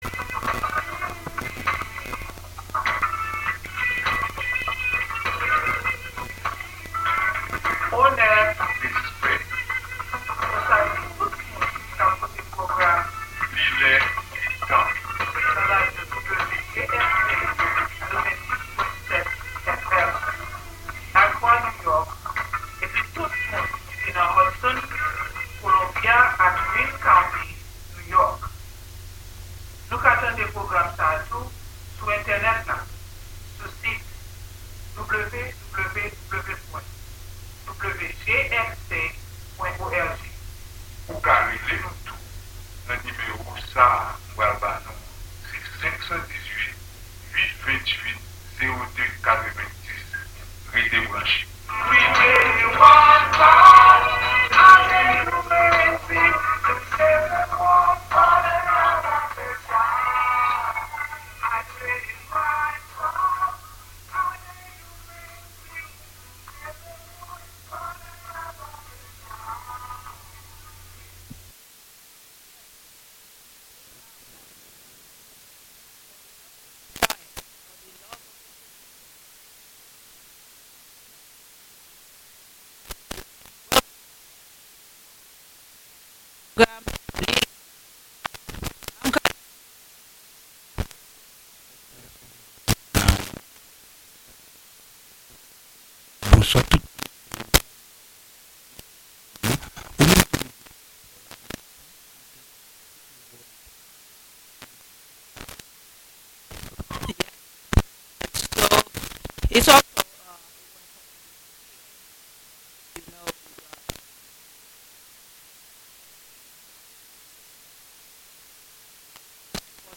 The show's mission is to promote Haitian language, music, arts and culture; to raise awareness and to discuss Haiti’s history and its relevance to today’s world; and to share news on current events happening here in the counties and in Haiti. Broadcast live from Columbia County, New York.